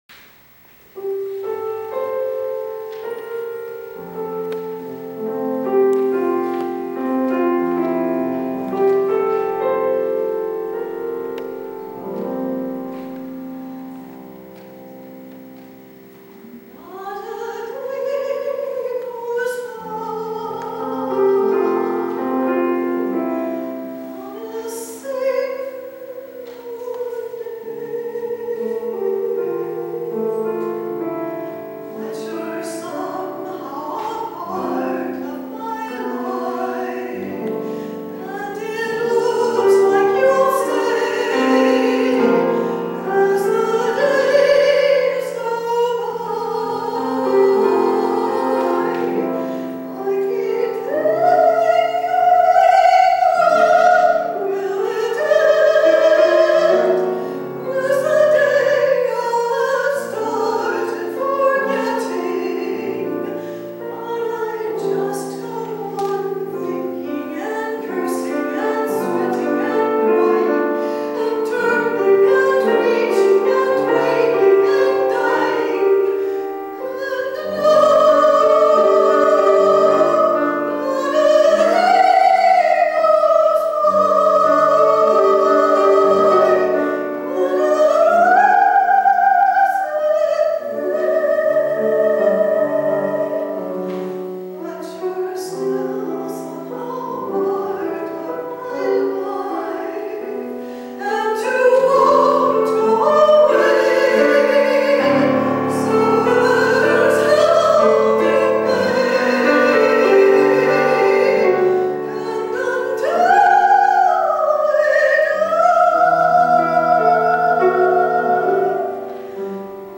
Performing at 2000 concert
In 2000, I peformed a concert in Palo Alto, CA. Below are audios of what I sang by clicking on a title listed below.